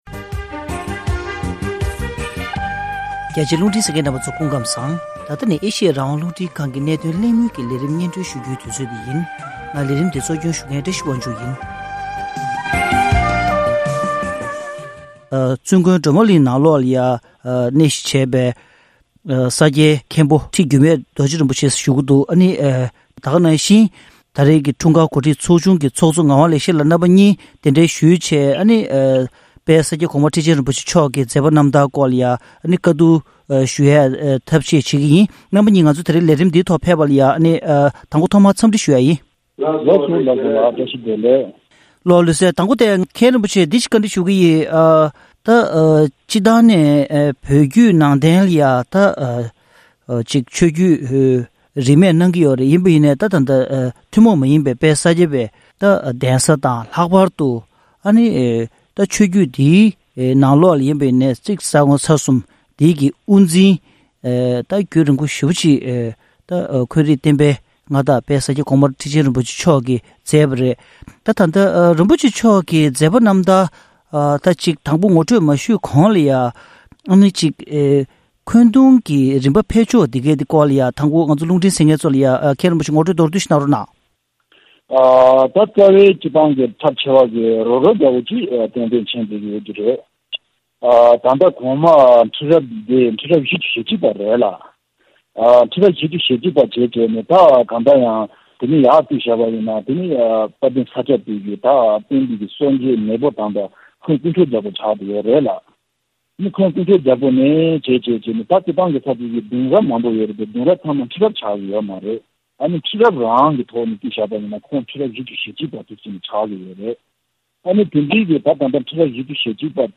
གནད་དོན་གླེང་མོལ